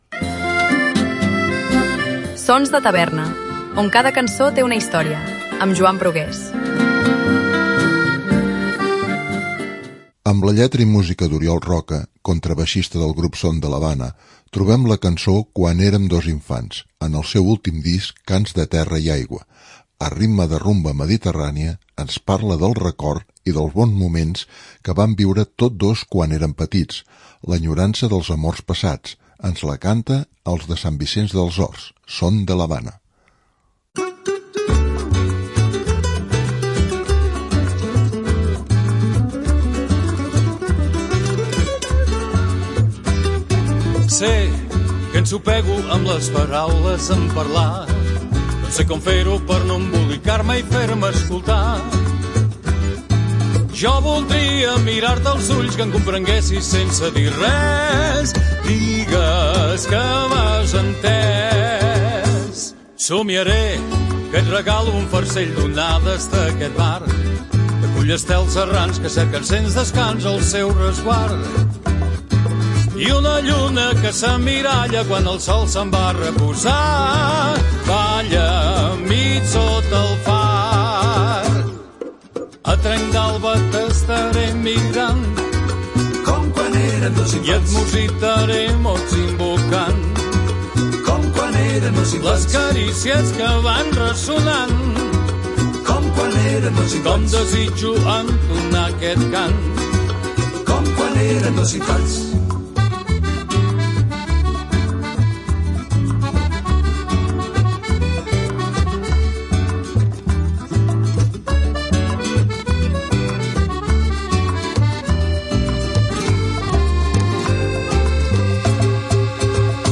a ritme de rumba mediterrània